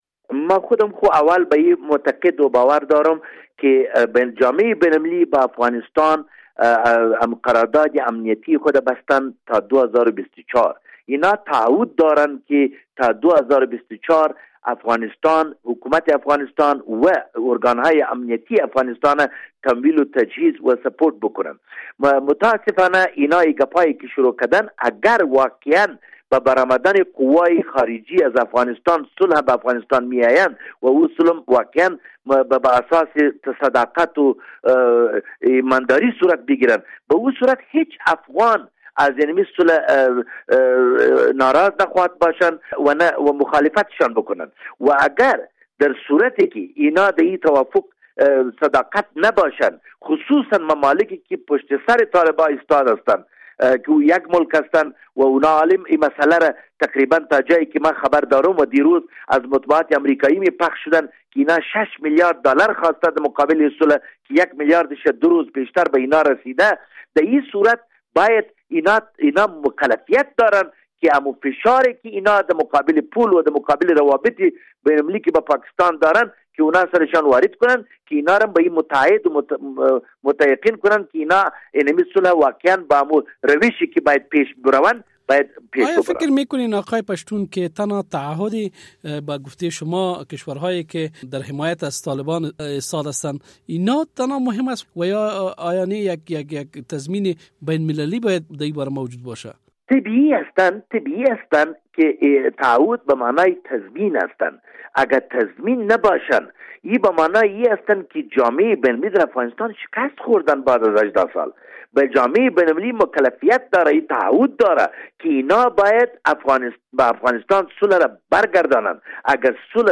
مصاحبه - صدا
خالد پشتون عضو کمیسیون امور دفاعی ولسی جرگه